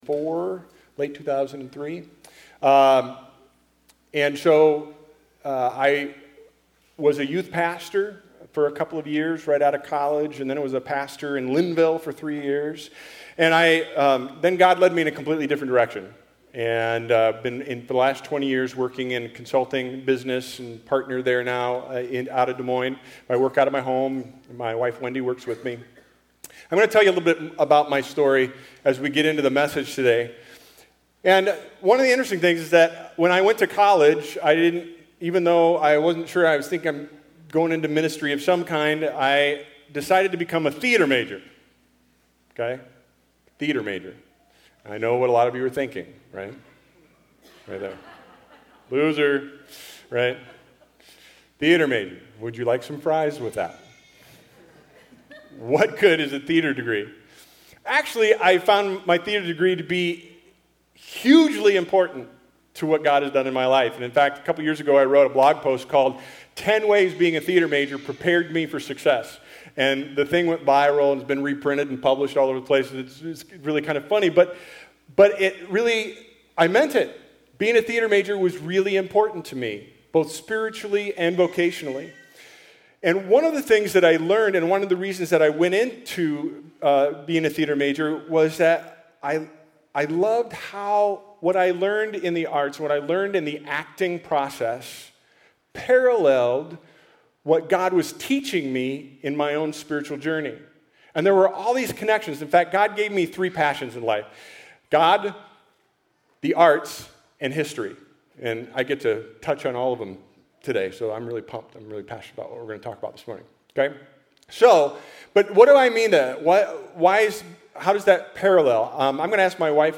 On June 29th I gave the kick-off message for a ten week series in the Third Church auditorium. The series is called “David: Medium Rare” which is really about the fact that while God called David “a man after my own heart” he certainly was not perfect or “completely done.”